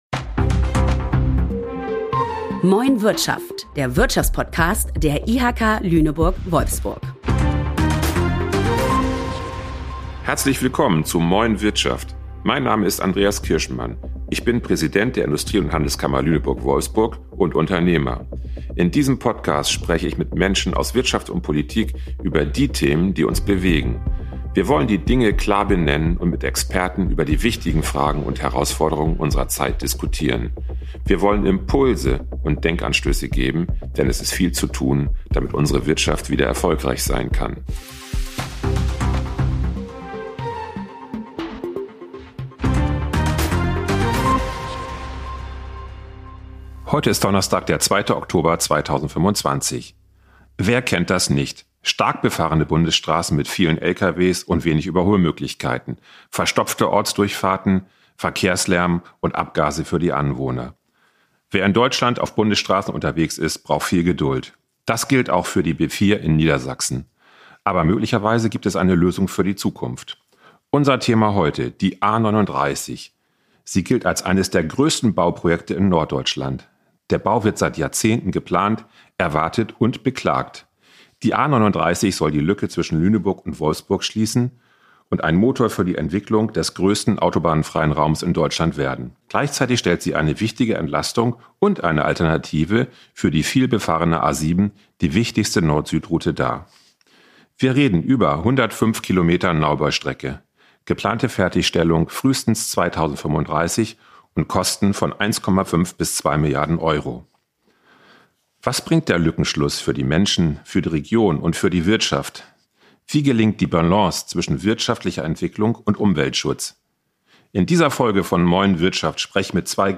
mit zwei Gästen, die sich deutlich kontrovers zu dem Bau der A37 positionieren